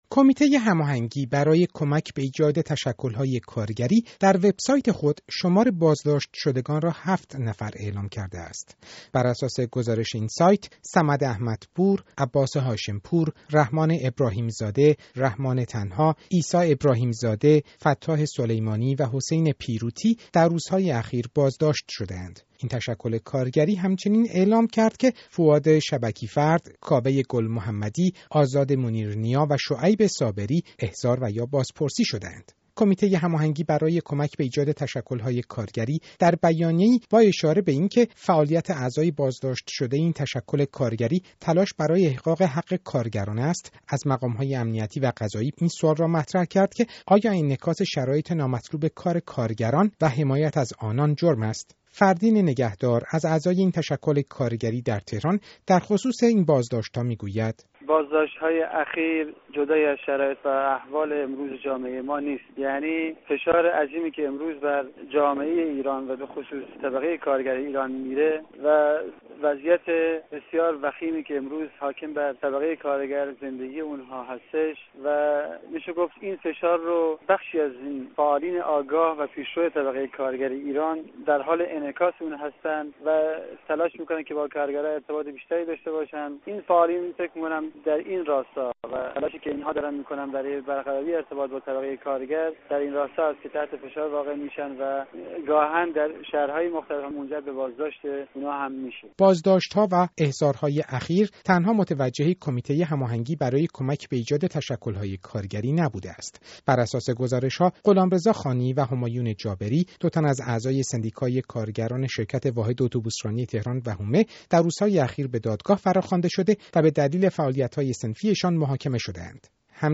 گزارش رادیویی در مورد بازداشت شماری از فعالان کارگری در شهرهای ایران